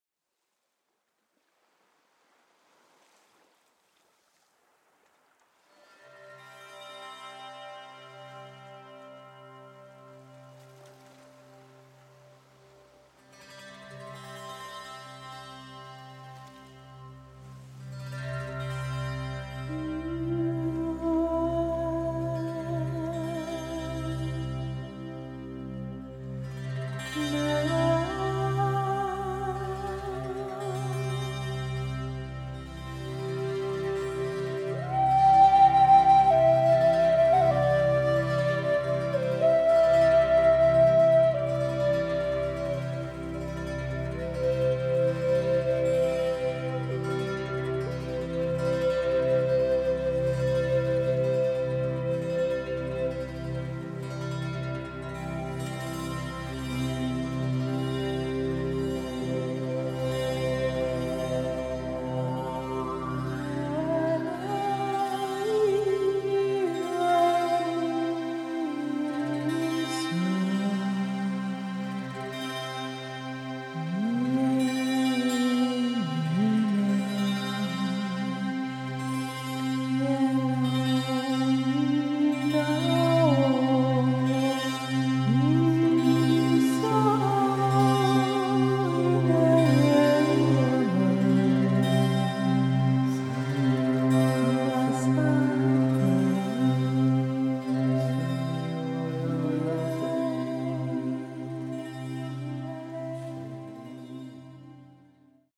Klanginstrumente, Keyboard und Gesang
Flöten, Keyboards, Saxophon und Stimme